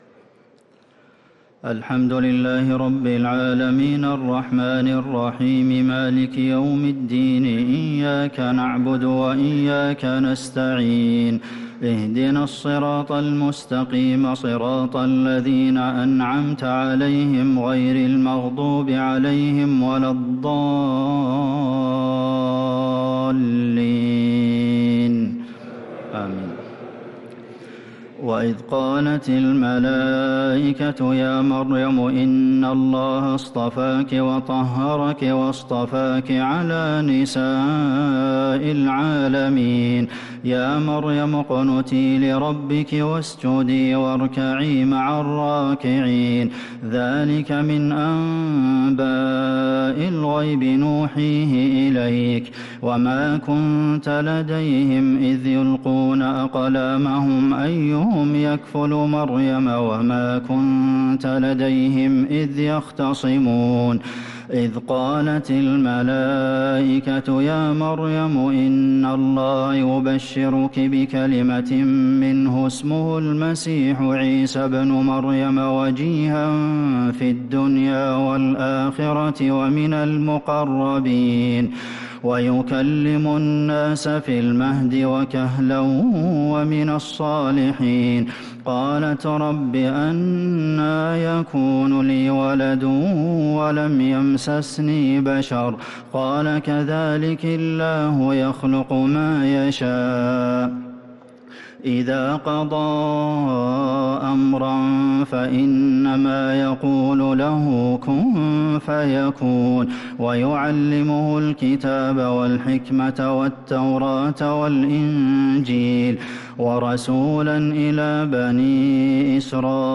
تراويح ليلة 4 رمضان 1446هـ من سورة آل عمران {42-92} Taraweeh 4th night Ramadan 1446H Surah Aal-i-Imraan > تراويح الحرم النبوي عام 1446 🕌 > التراويح - تلاوات الحرمين